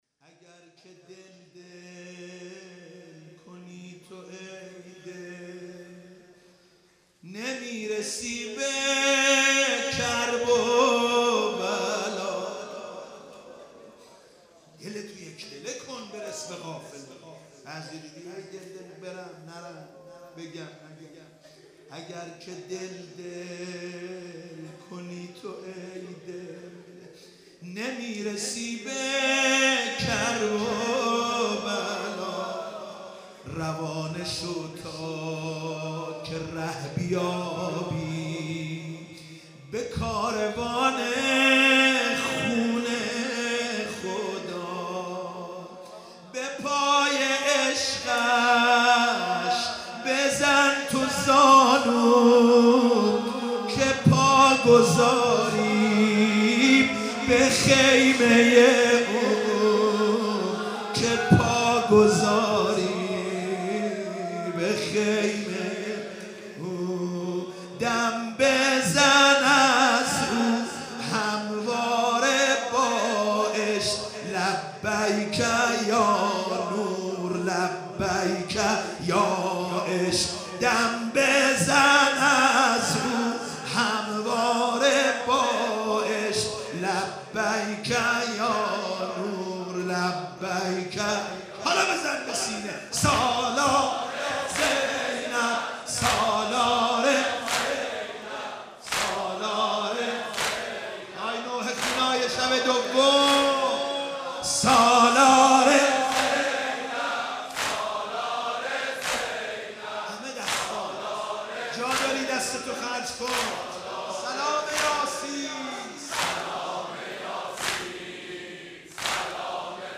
شب دوم محرم 96 -نوحه - اگر که دل دل کنی تو ای دل